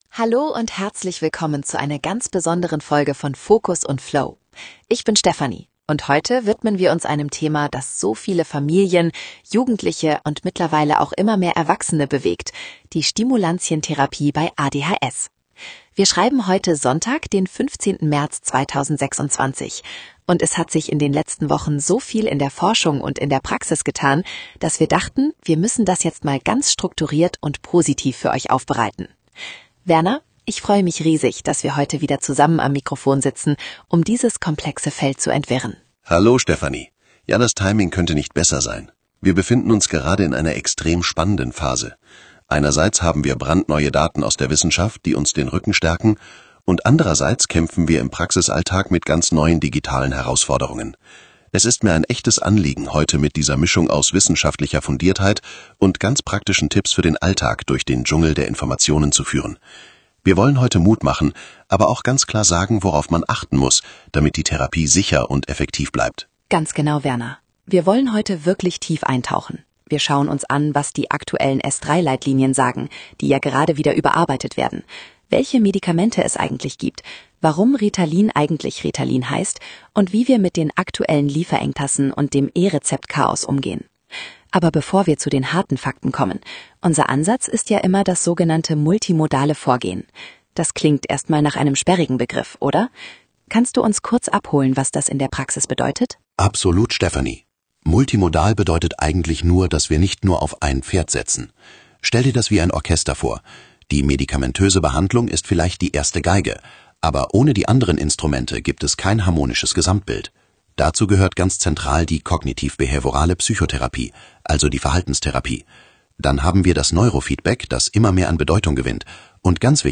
Patienten- und Elterninformationen als Podcasts
Diese bereiten, KI-generiert auf Basis unserer fundierten klinischen Erfahrungen aus 25 Jahren Berufstätigkeit, Wissensgrundlagen zu Medikamenten, Therapieverfahren oder Störungsbildern und Behandlungsempfehlungen auf.